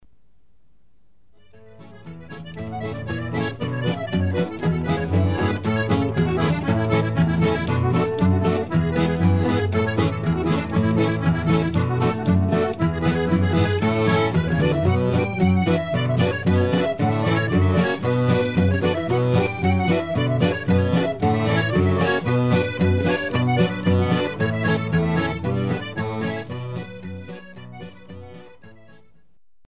Dalle musiche da ballo della tradizione popolare emiliana,
DEMO mp3 - Frammenti brani registrazione live